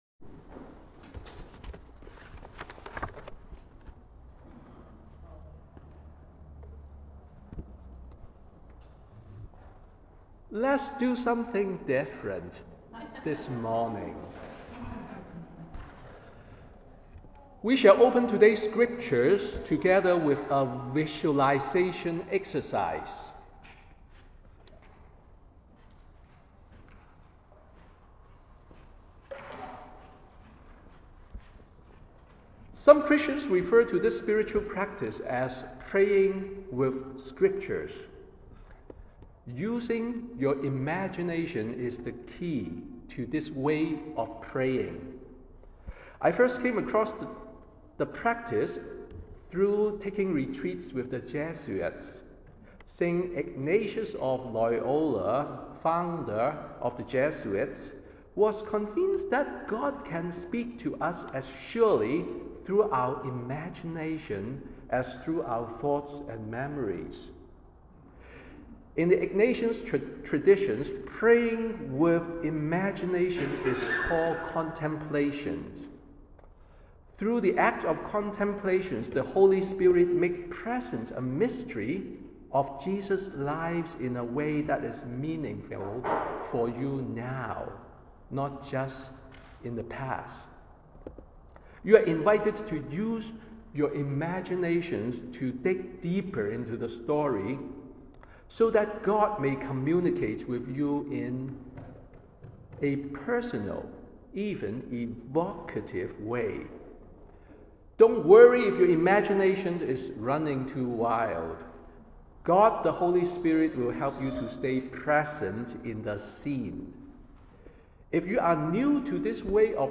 Sermon on the 6th Sunday after Epiphany